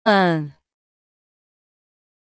File:Phoneme (Commonscript) (Accent 0) (10) (Female).mp3
Audio of the phoneme for Commonscript letter 10 (pronounced by female).